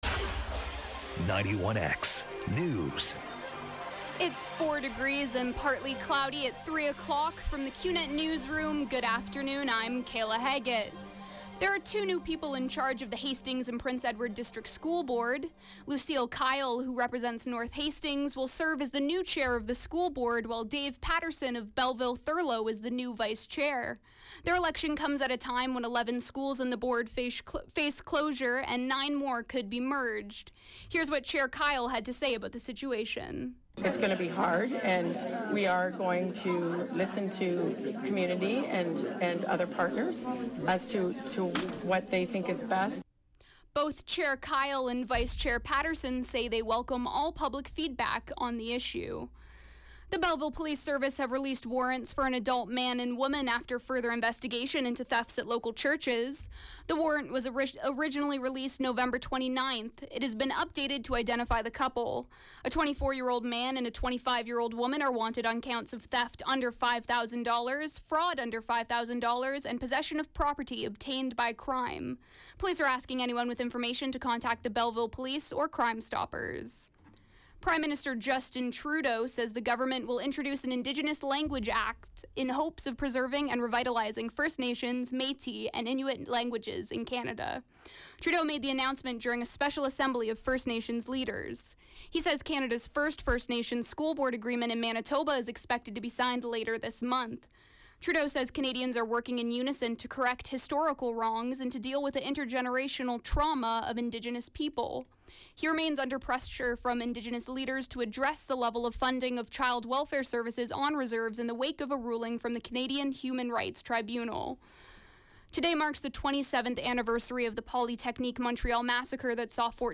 91X Newscast – Tuesday, Dec. 6, 2016, 3 p.m.